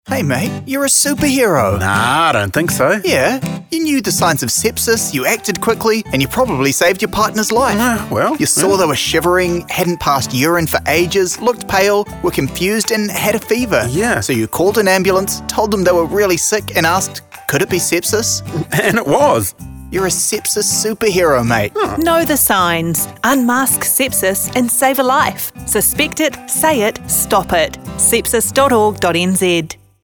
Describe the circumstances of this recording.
We were fortunate to receive a sponsored radio campaign from The Breeze and MORE FM (Mediaworks). Have a listen to our advertisement that played throughout the month of September 2025.